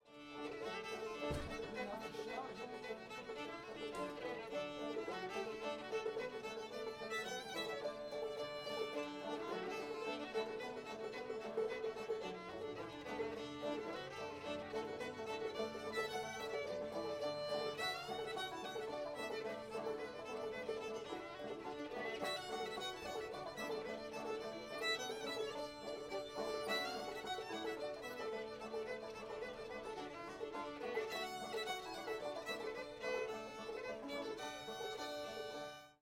sweets o'weaver [D]